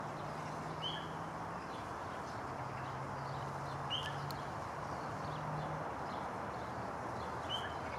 Help identify this bird (song), please!